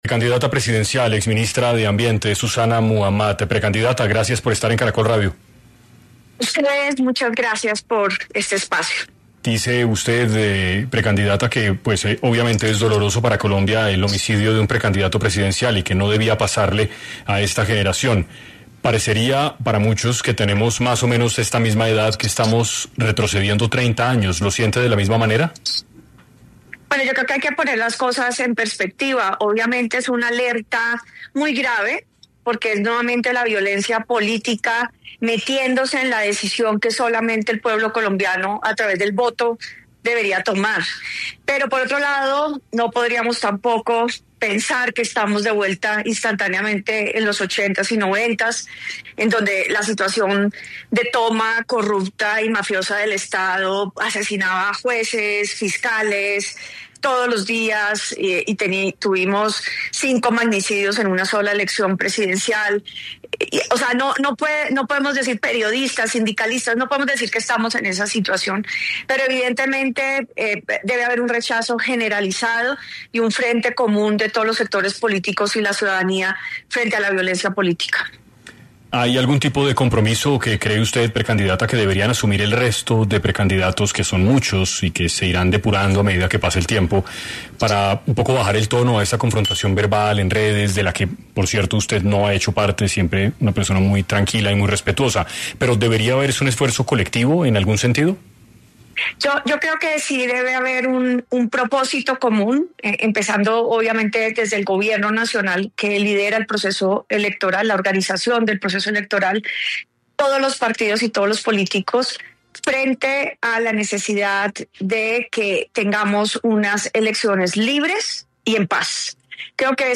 En 6AM de caracol radio estuvo la precandidata presidencia, Susana Muhammad, quién habló sobre el fallecimiento de Miguel Uribe y dio detalles de lo piensa frente a la situación, dejando en claro que el camino del odio y de atacar al otro no será una “herramienta”, que brinde unidad.